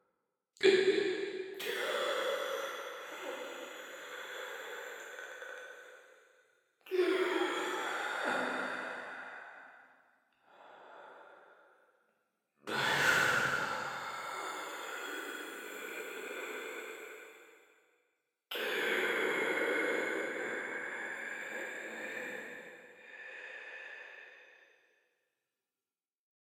PrisonVoices.ogg